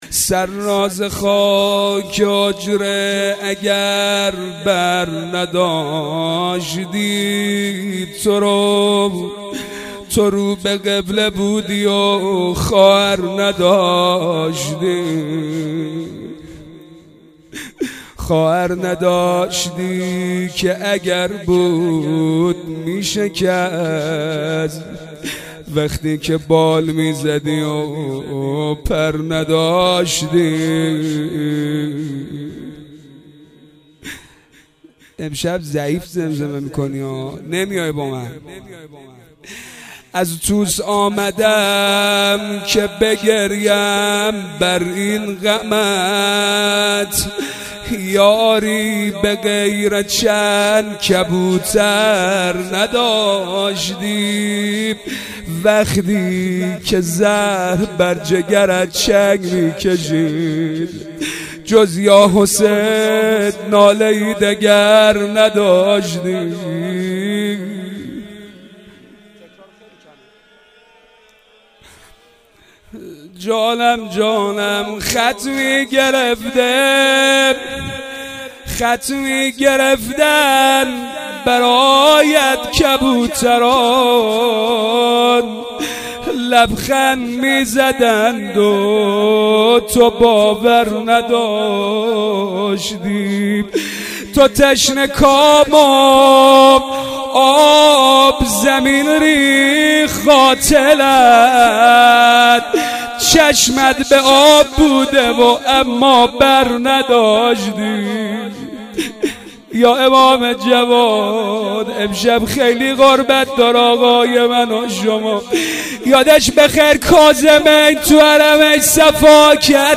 روضه امام جواد عليه السلام ...